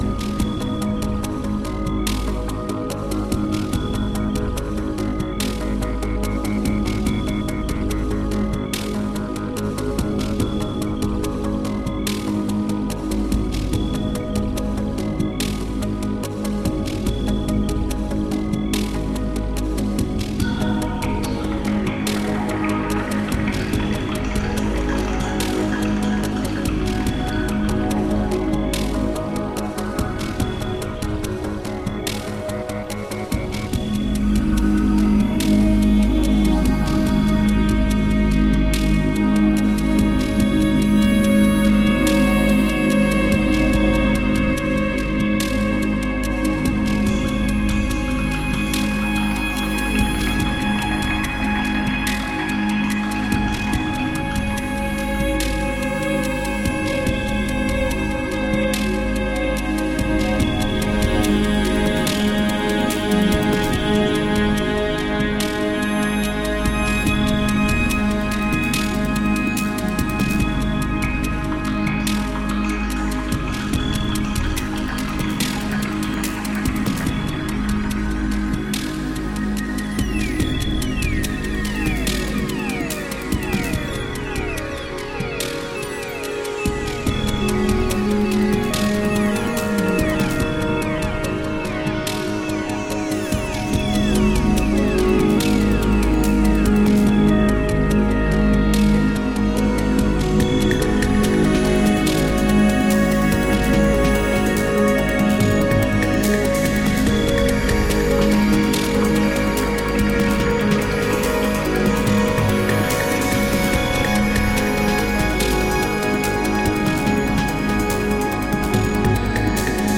Ambient/idm with a dash of dub.
A live recording of the sold-out
airy, futuristically floating compositions
is both peaceful and hypnotic, serene and radiant.
Tagged as: Electronica, Other, IDM, Psychedelic trance